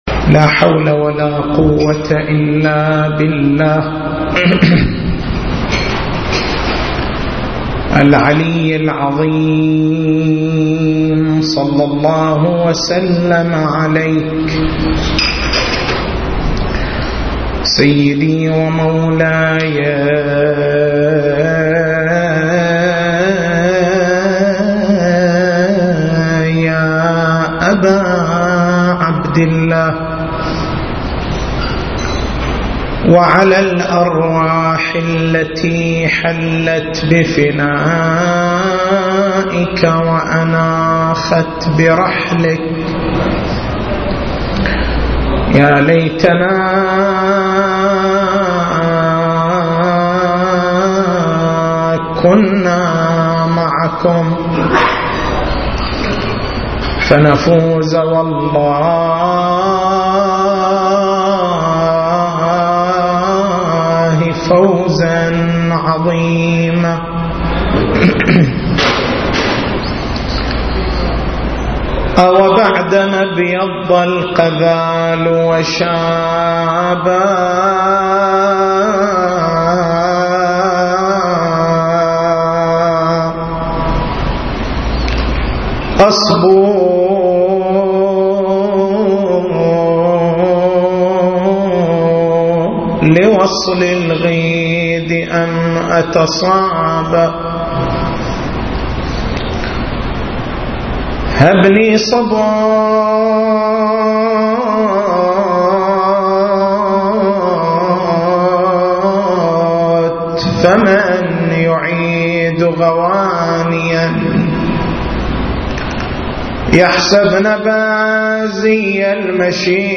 تاريخ المحاضرة: 13/09/1433 نقاط البحث: هل الأذان تشريع سماوي؟